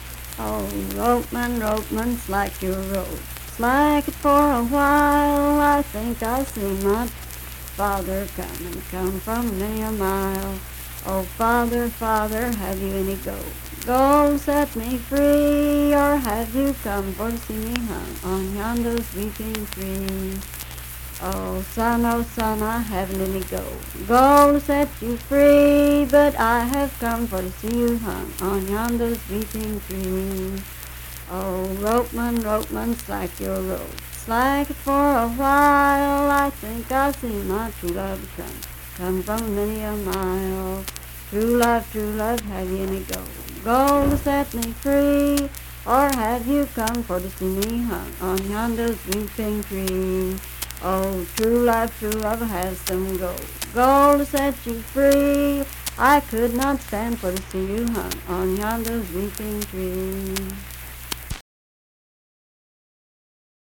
Unaccompanied vocal music
Voice (sung)
Sutton (W. Va.), Braxton County (W. Va.)